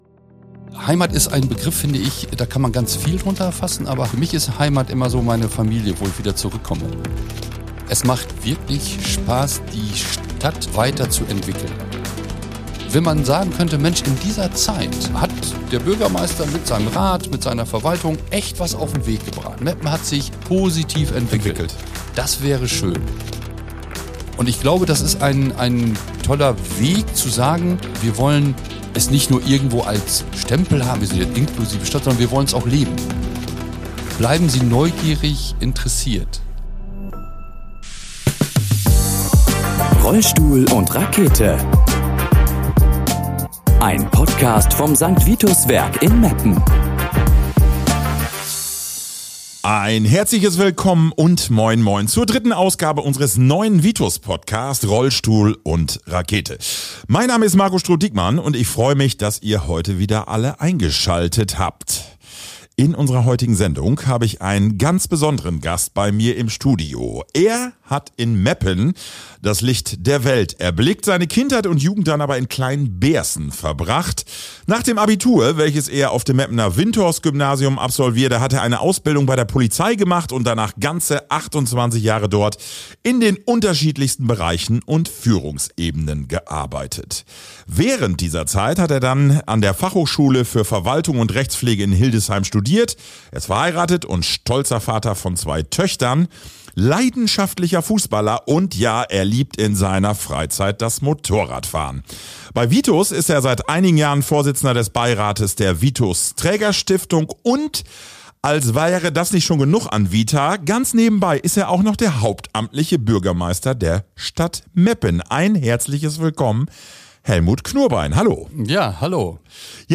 In unserer heutigen Ausgabe haben wir den Bürgermeister der Stadt Meppen, Helmut Knurbein zu Gast.